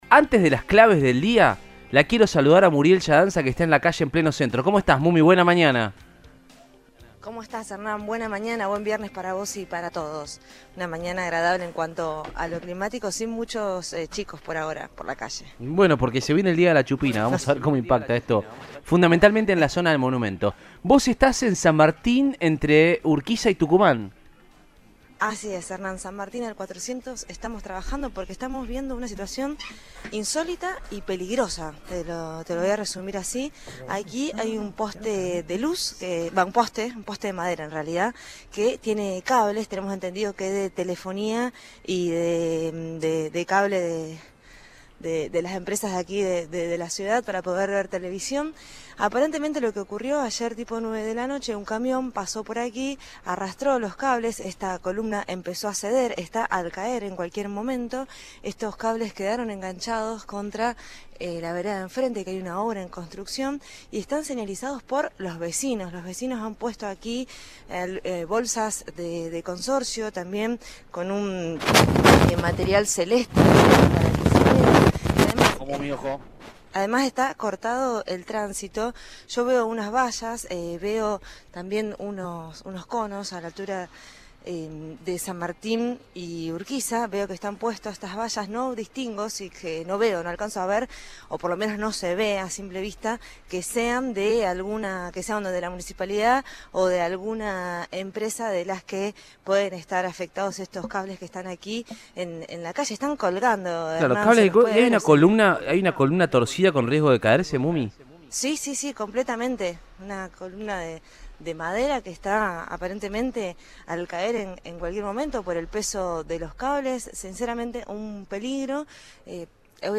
en el móvil de Cadena 3 Rosario